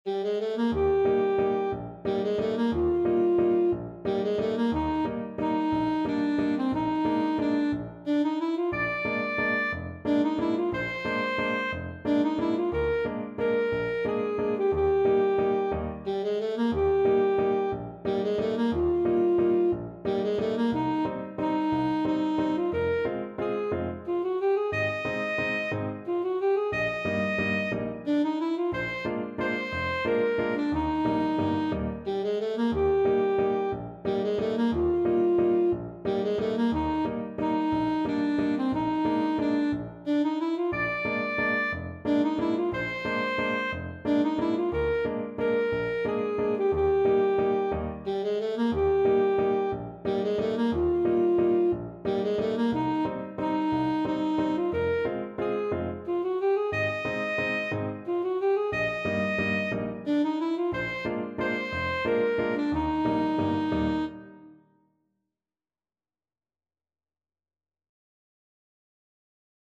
Alto Saxophone
One in a bar .=c.60
3/4 (View more 3/4 Music)